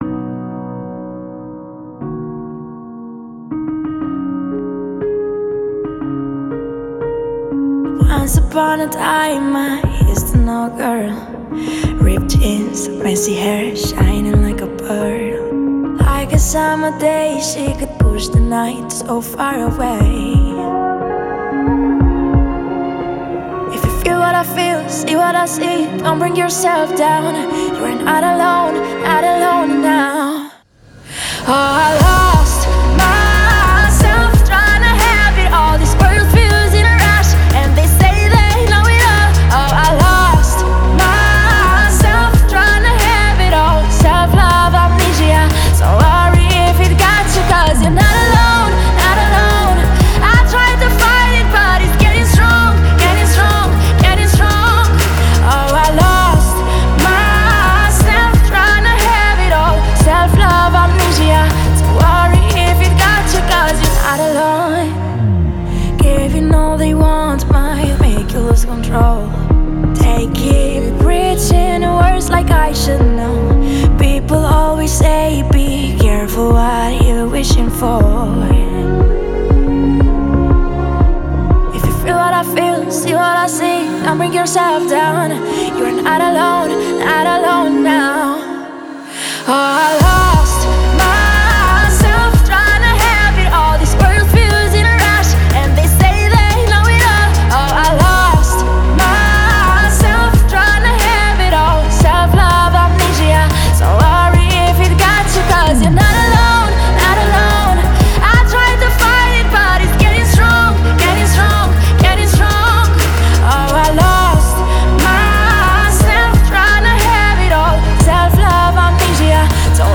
эмоциональная поп-песня